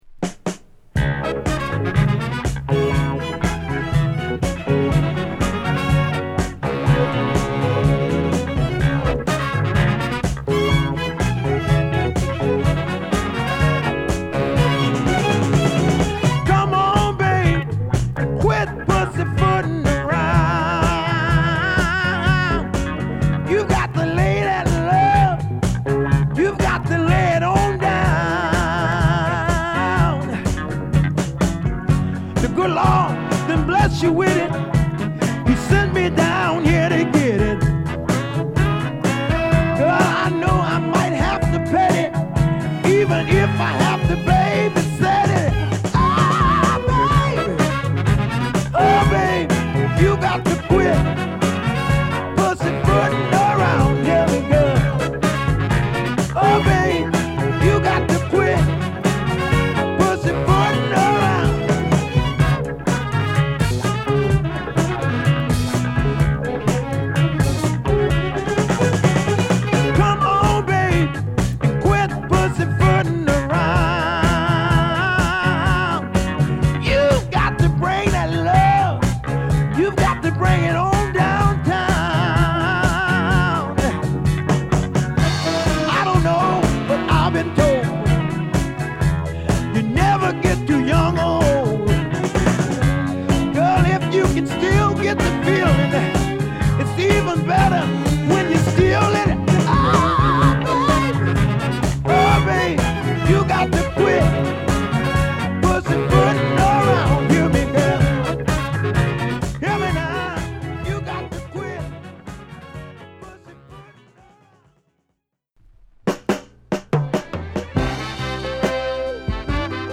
アルバム通してホッコリとした南部の暖かさを感じるサザン・ソウル好作！